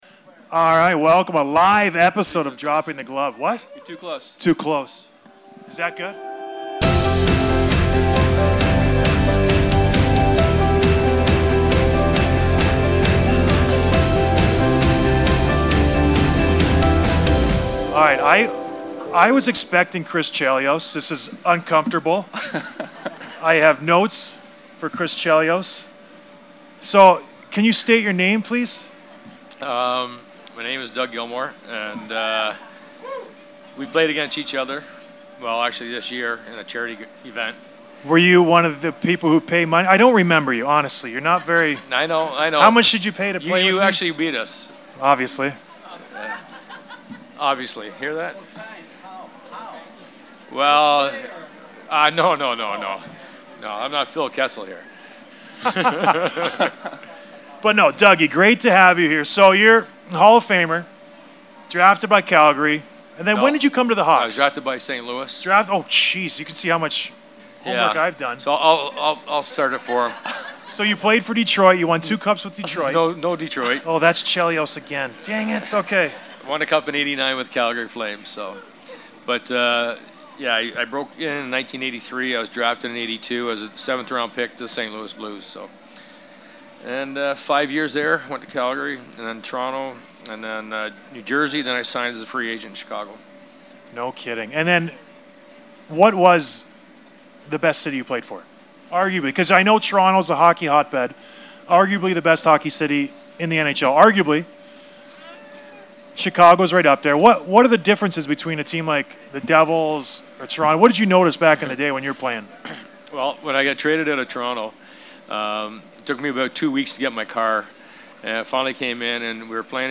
Blackhawks Convention Interview with Doug Gilmour